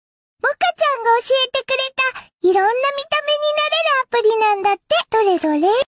Crying Sound Effects Free Download